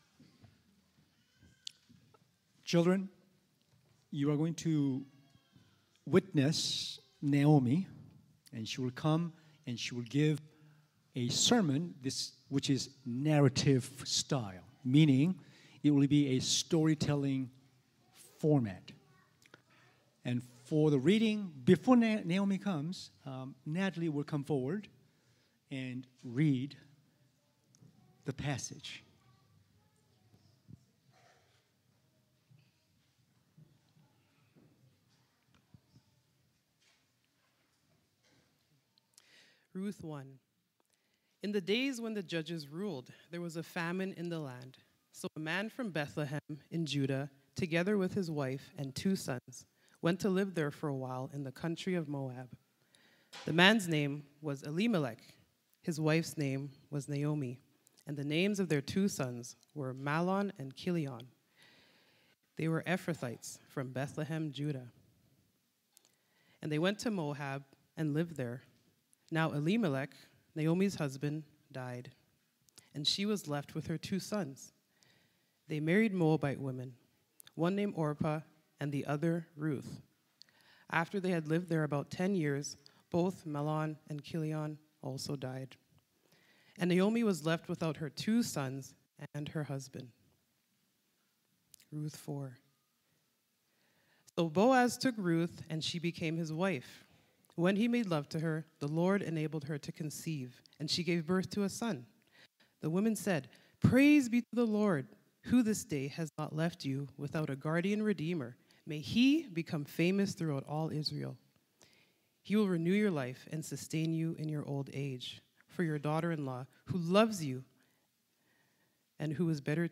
Current Sermon Naomi didn't see that coming!
Guest Speaker September 29, 2024 Rahab didn't see that coming!